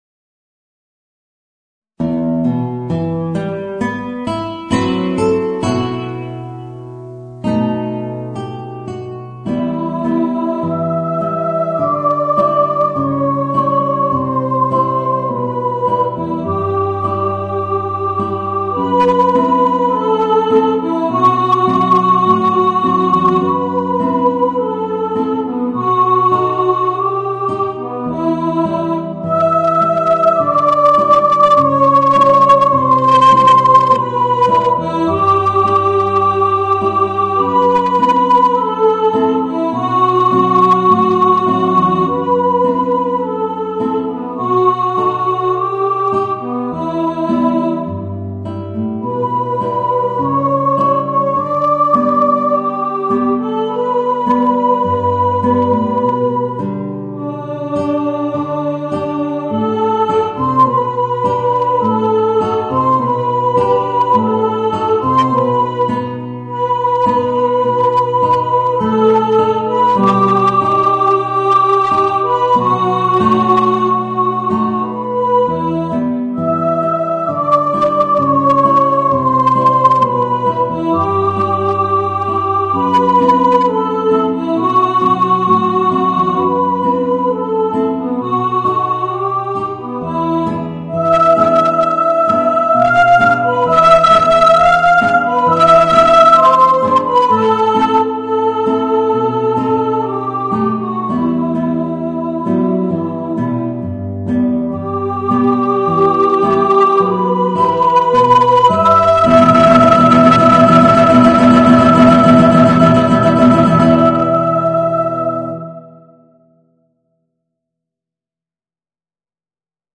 Voicing: Guitar and Mezzo-Soprano